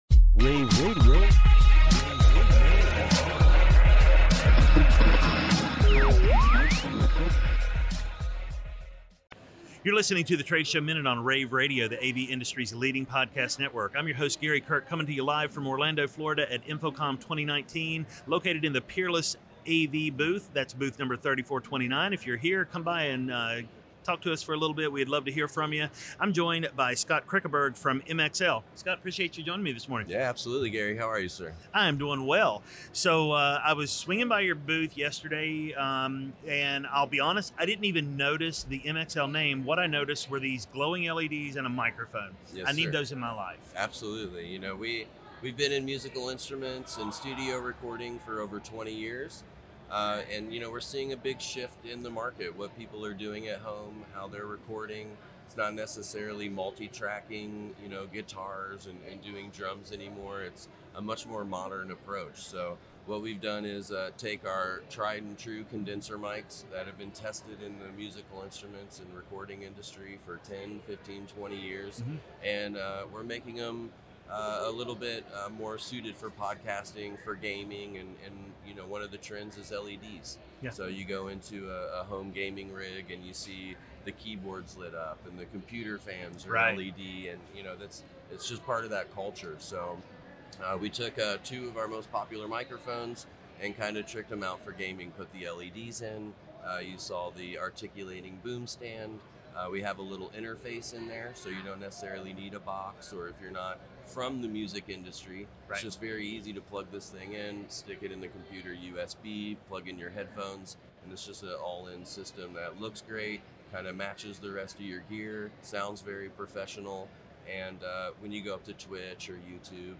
June 14, 2019 - InfoComm, InfoComm Radio, Radio, The Trade Show Minute,